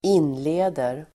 Uttal: [²'in:le:der]